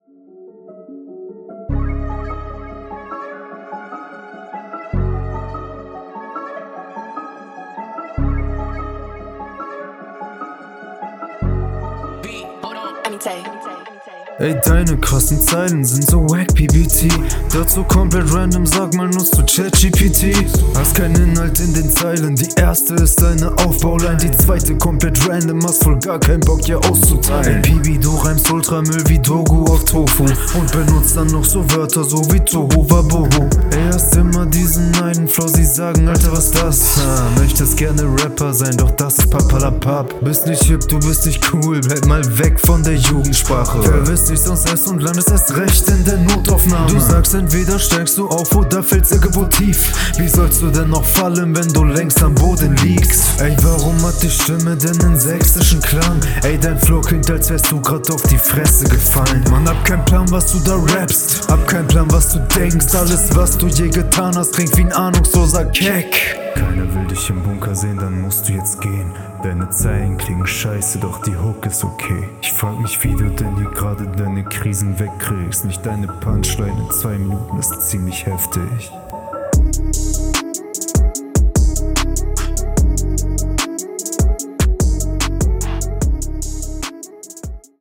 Runde kommt schön arrogant.
Auf dem beat kommst du viel besser sehr nice
Schöner Beat und du baust in der Runde eine Atmosphäre auf, die dir gut gelungen …